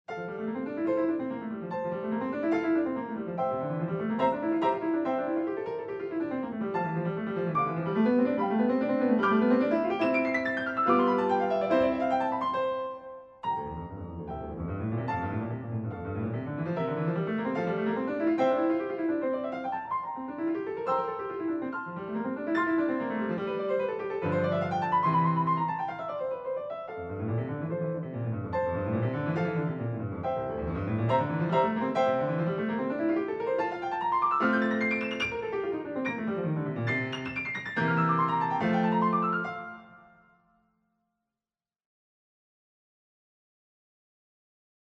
Etude pour Piano